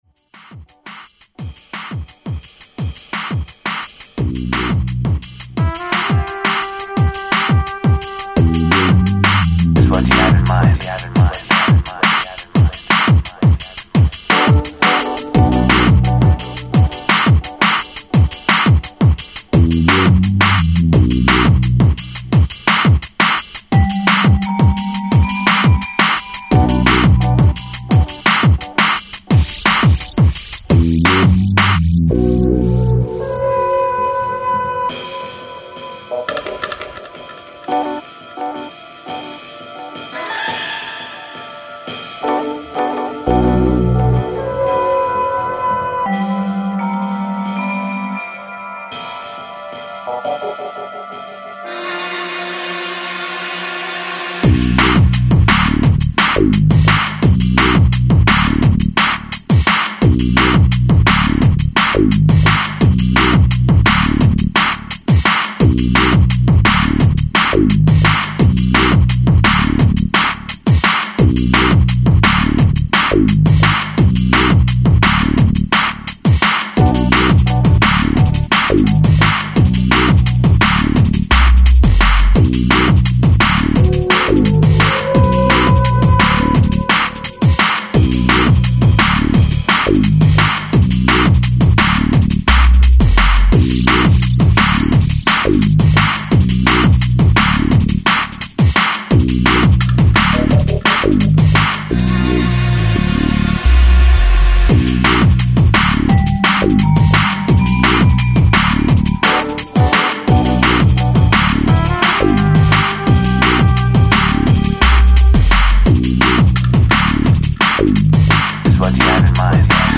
futuristic dark sounds
early Techstep scene in DNB